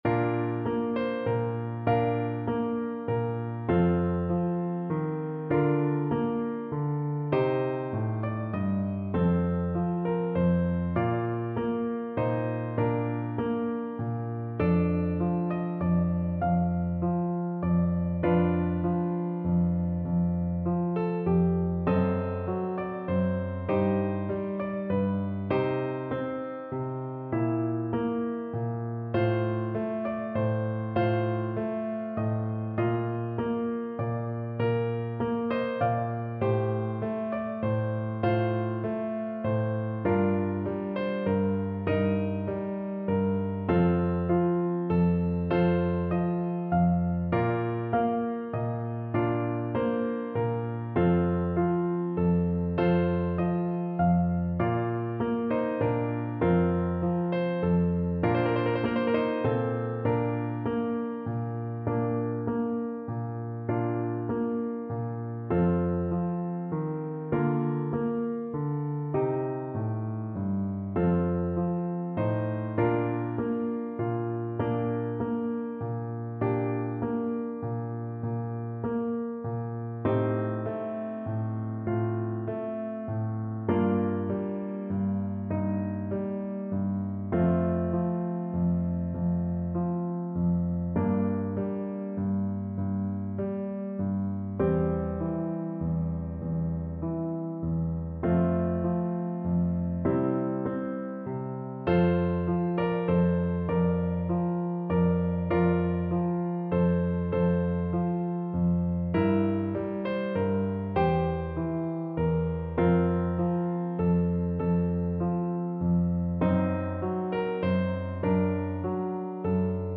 Play (or use space bar on your keyboard) Pause Music Playalong - Piano Accompaniment Playalong Band Accompaniment not yet available transpose reset tempo print settings full screen
French Horn
12/8 (View more 12/8 Music)
Bb major (Sounding Pitch) F major (French Horn in F) (View more Bb major Music for French Horn )
. = 44 Largo
Classical (View more Classical French Horn Music)